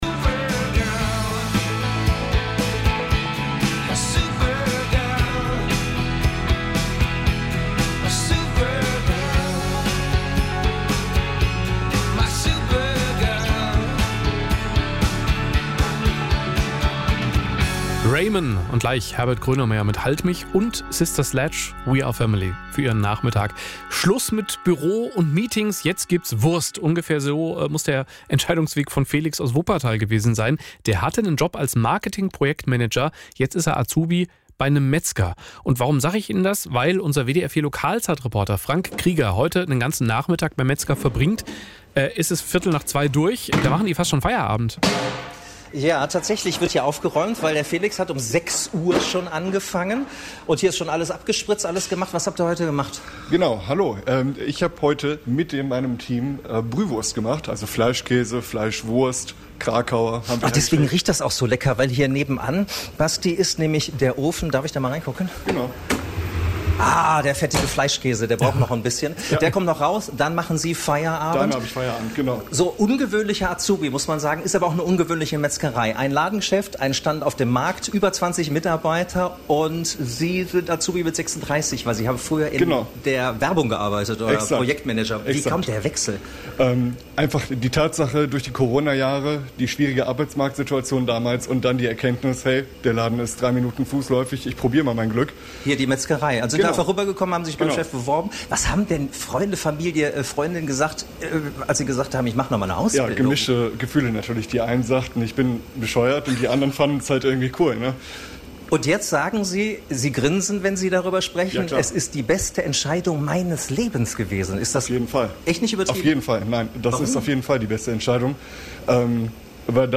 Azubi beim Metzger – Ein Mitschnitt des WDR 4 Beitrags